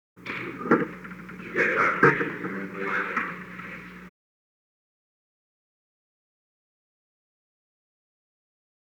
Secret White House Tapes
Conversation No. 919-1
Location: Oval Office
The President met with an unknown man.